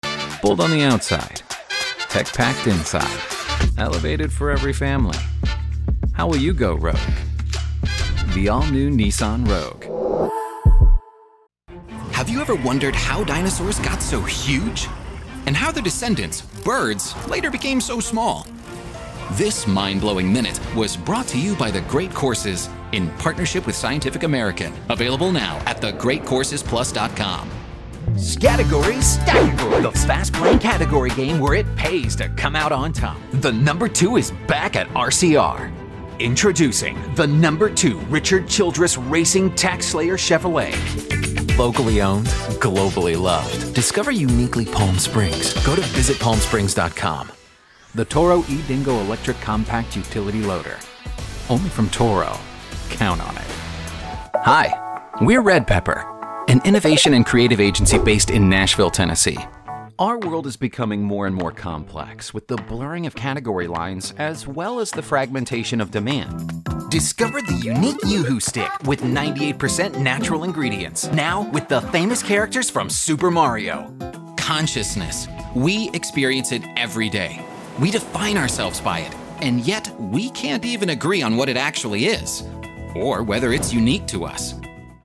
Has Own Studio
COMMERCIAL 💸